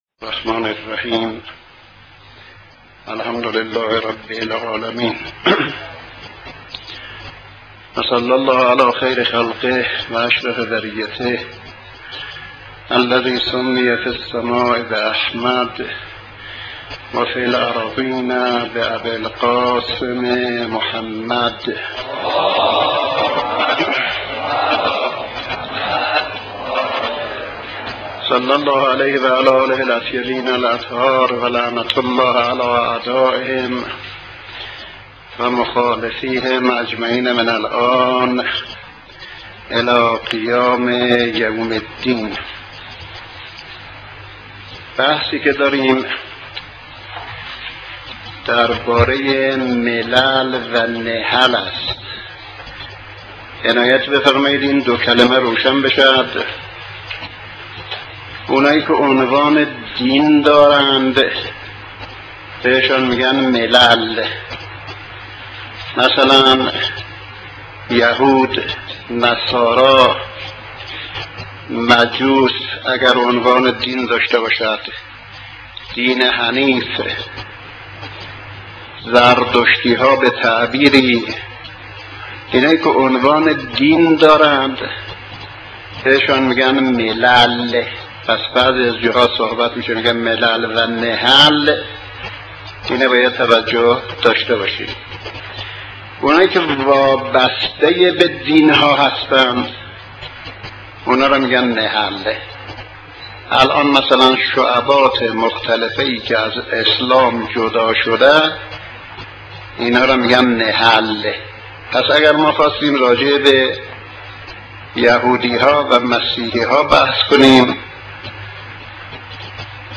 سخنرانی حجت الاسلام و المسلمین با بررسی گذرا پیرامون ملل و نحل و بررسی عقاید نا درست مسیحیت